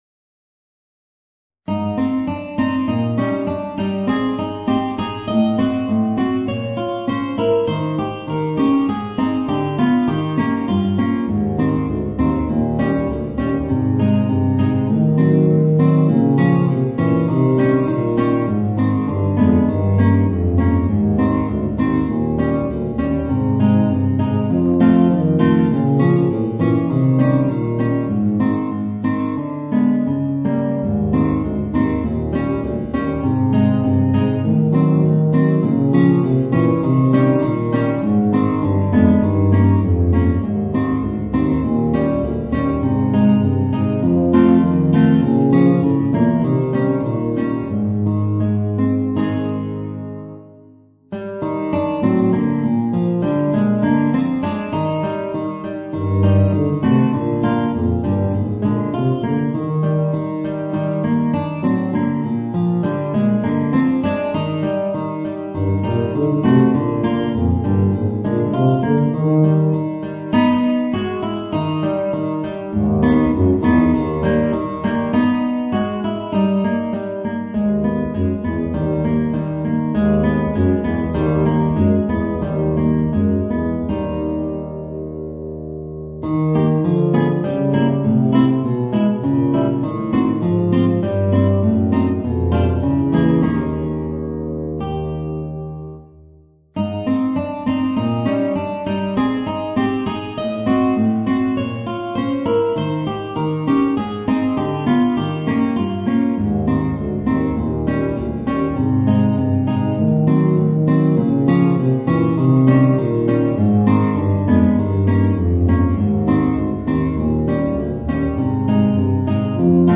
Voicing: Bb Bass and Guitar